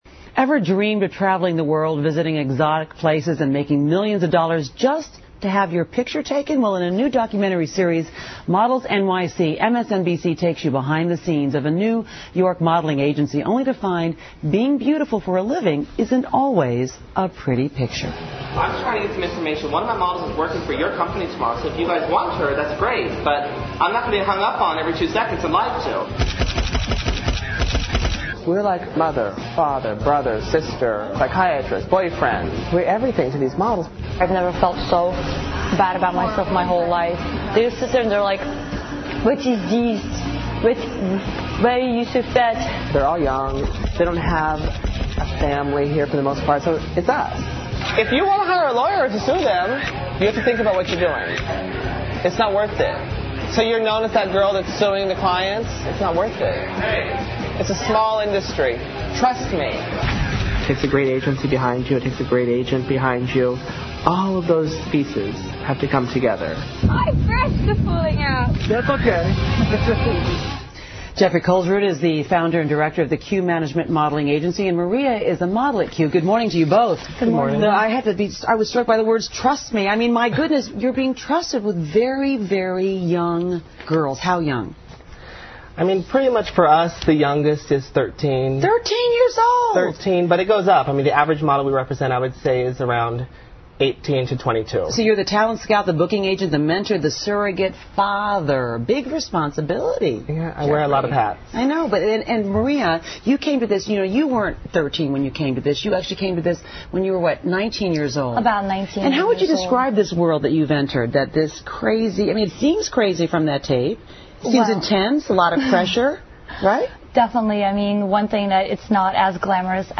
访谈录 Interview 2007-04-24&26, 还模特真实面目 听力文件下载—在线英语听力室